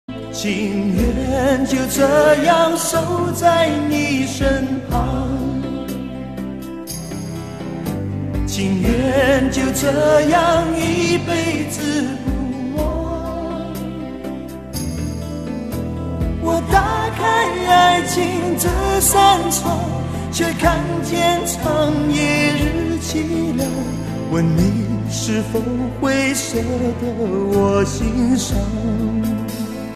粤语